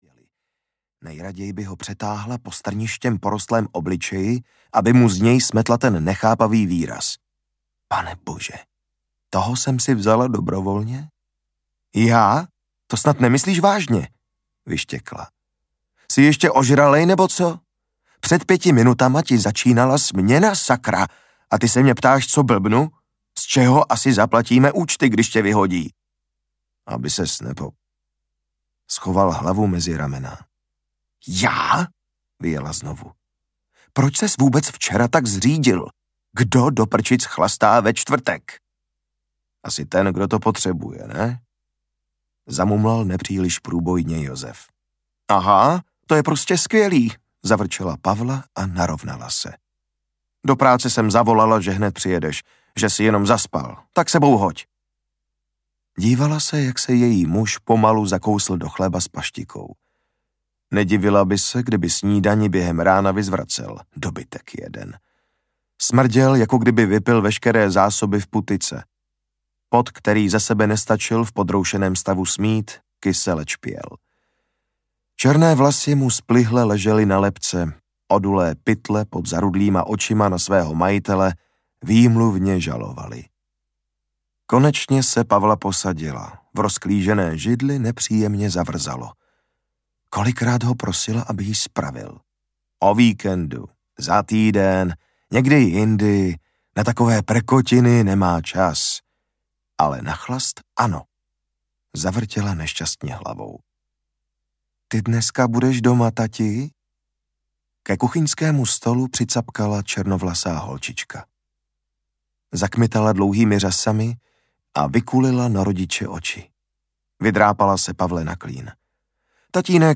Vodník audiokniha
Ukázka z knihy